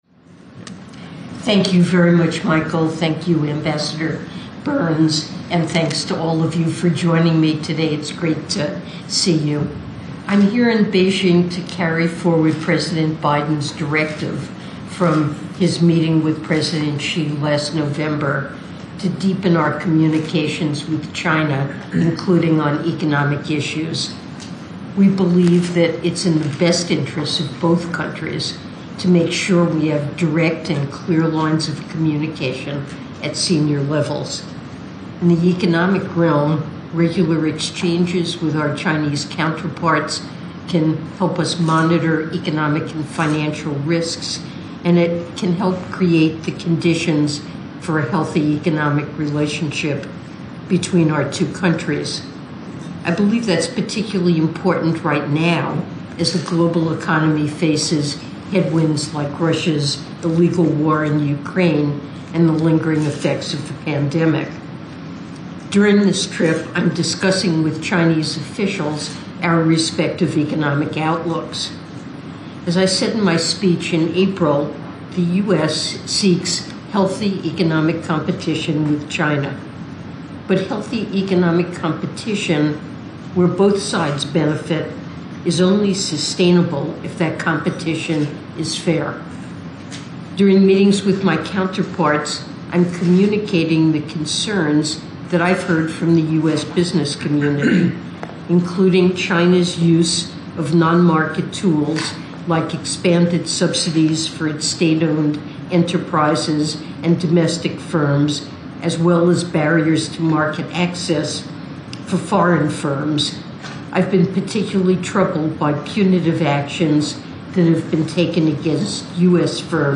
Opening Remarks at Roundtable Discussion with U.S. Businesses Operating in the People’s Republic of China
delivered 7 July 2023, Beijing, China